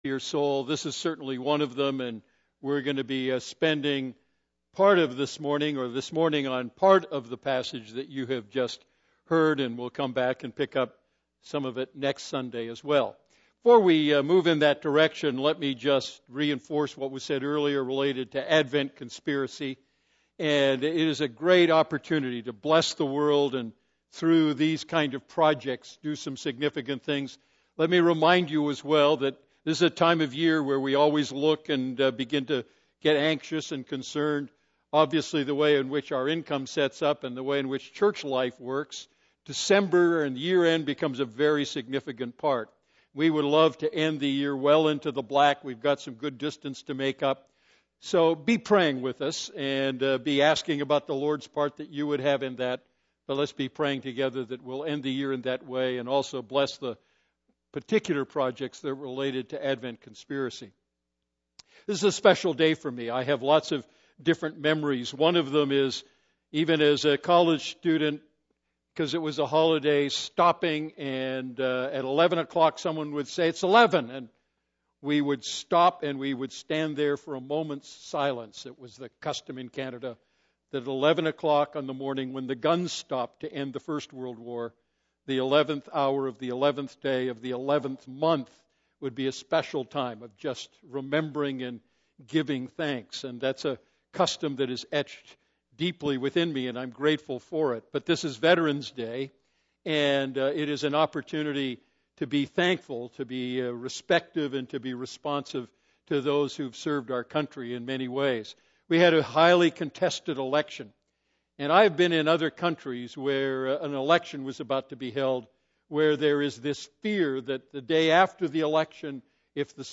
A message from the series "Going for the Gold."